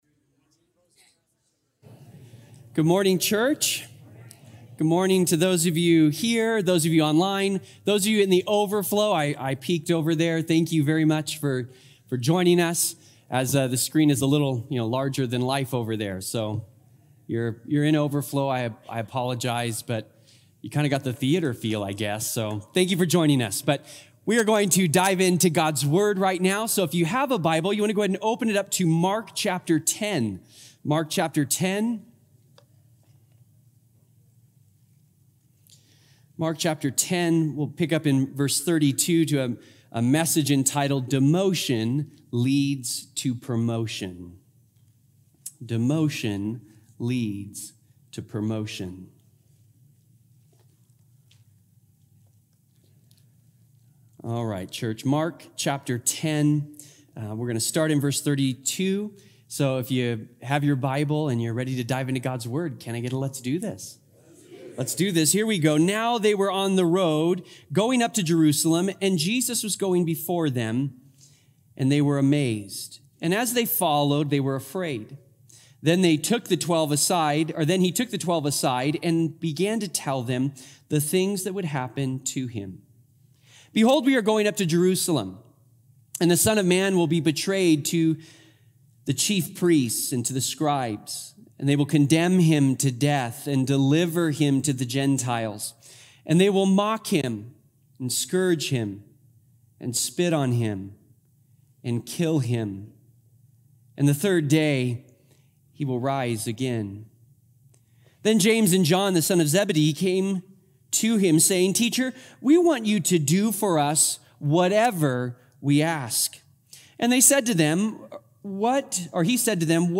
Sermon Details Calvary Chapel High Desert